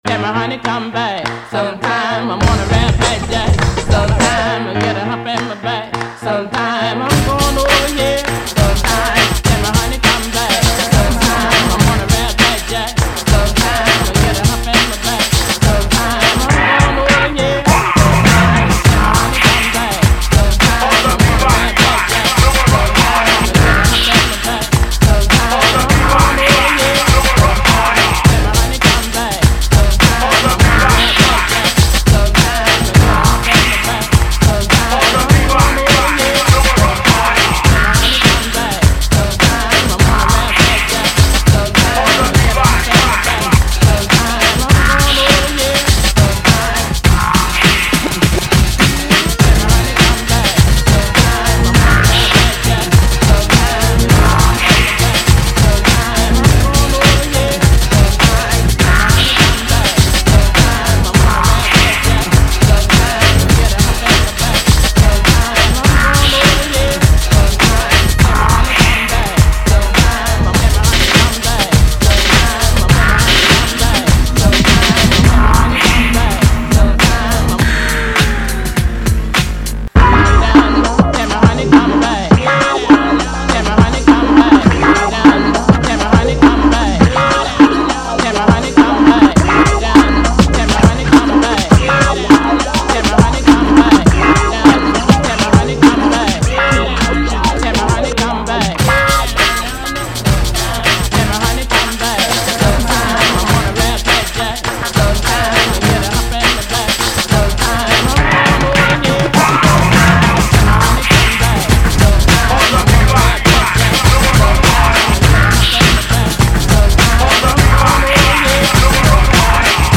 Справка: Кислота - направление в электронной музыке.